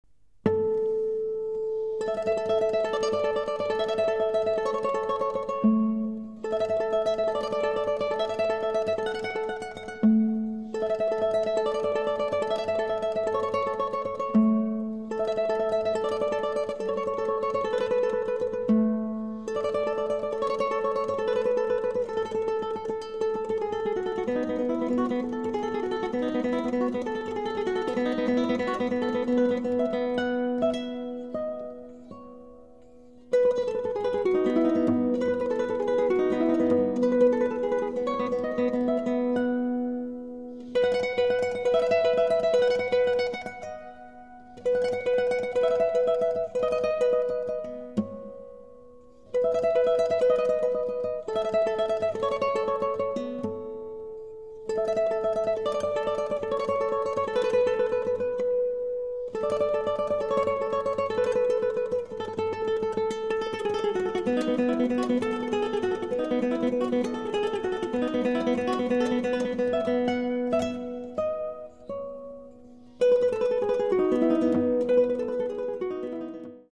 Gitarre solo